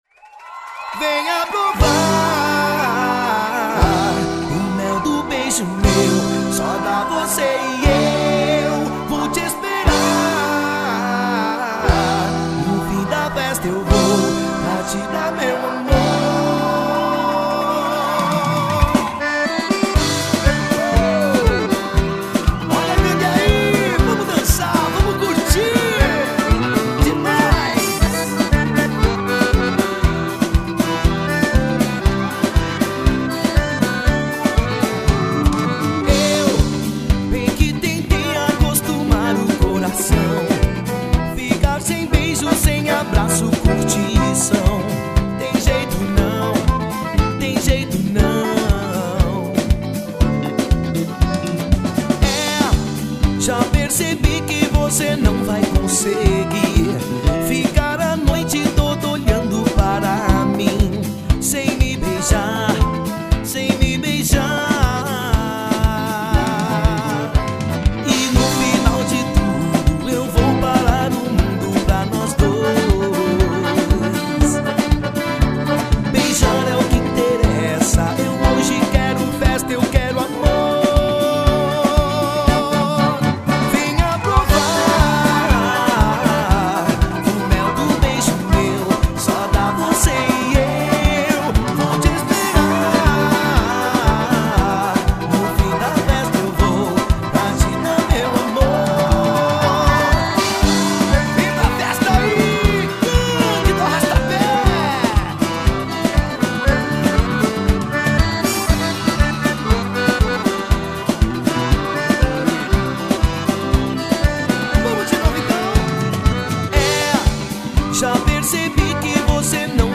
Batidão Gaúcho.